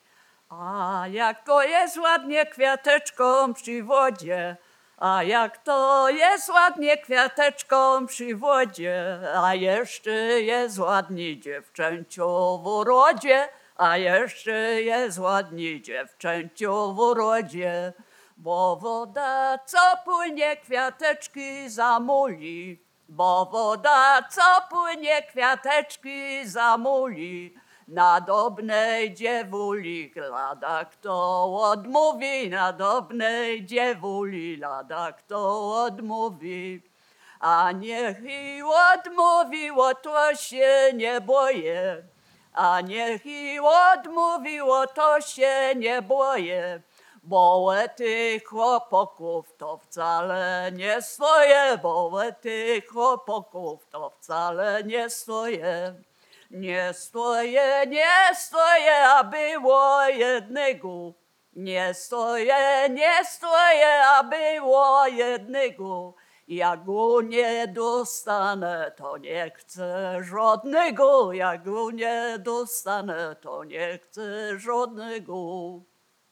Biskupizna
Obyczajowa